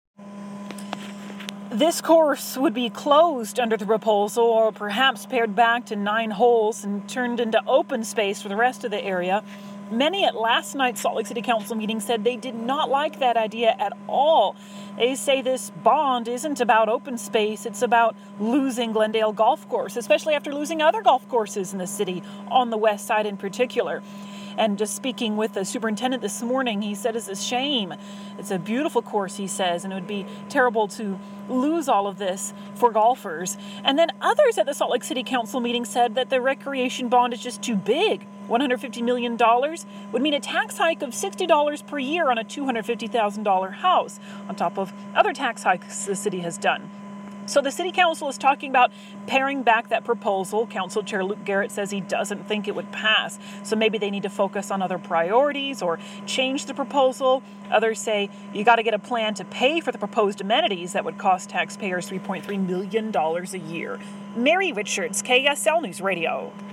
is at Glendale Golf Course with more reaction.